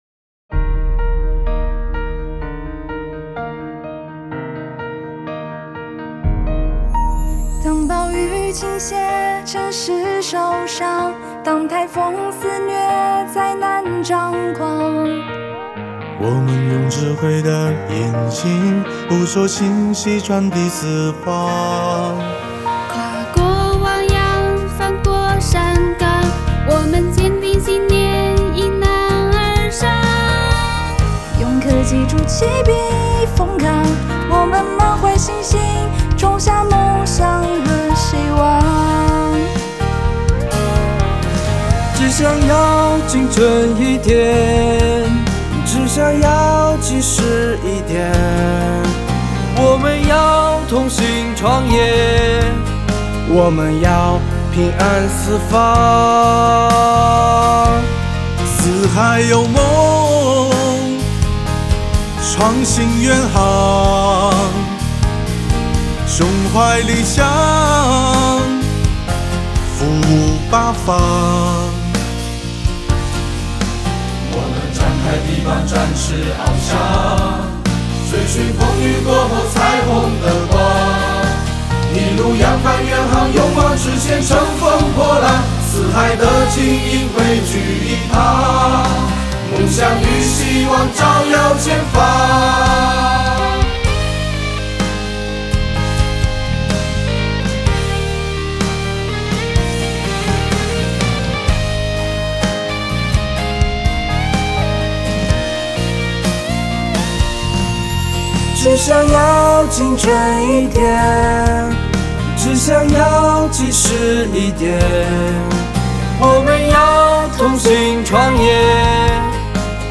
最终由五位四创员工倾情献唱
歌曲旋律催人奋进